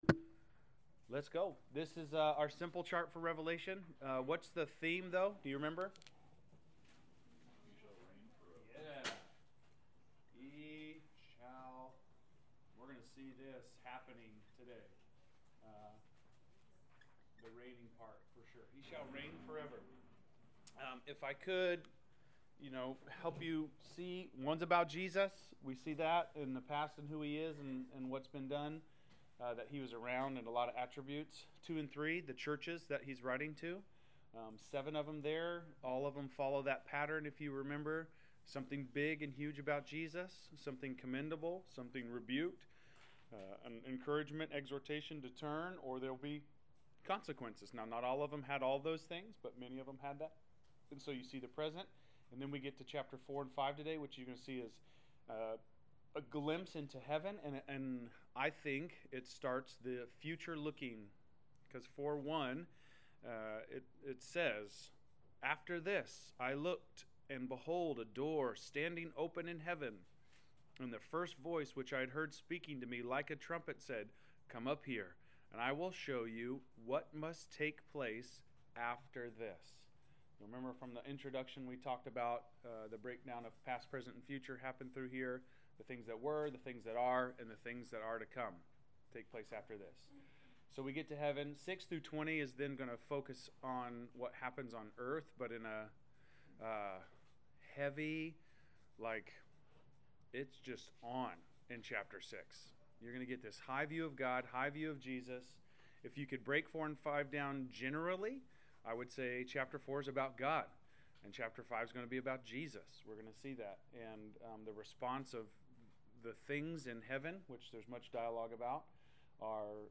Class Session Audio February 25